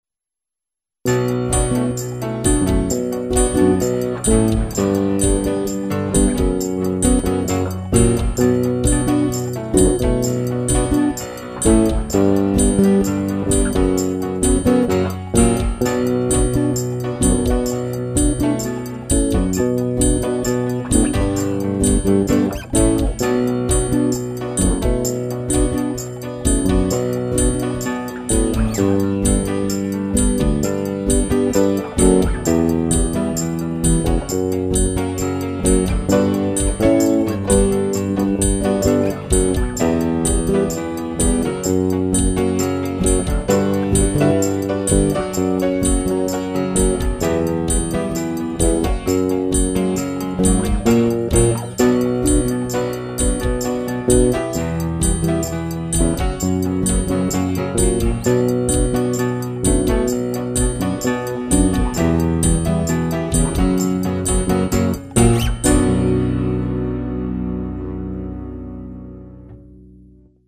No Vocals